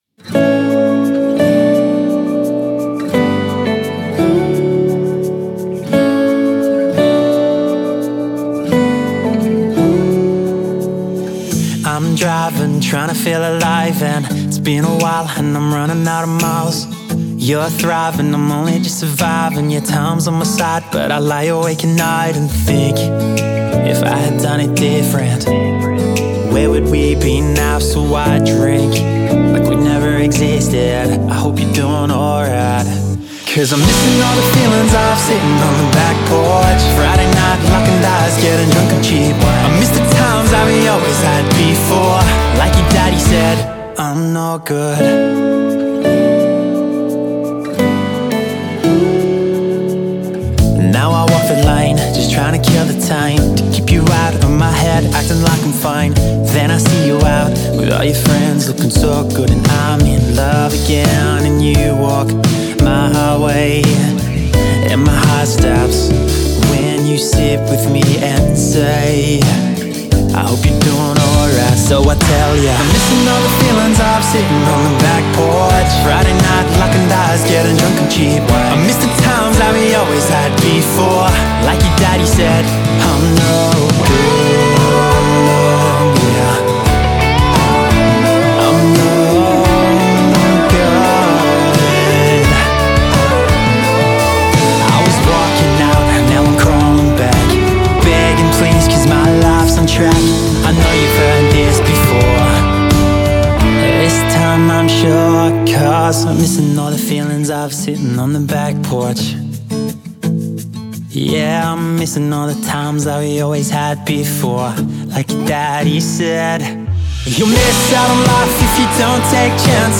Emotional but energetic. Deep but hopeful.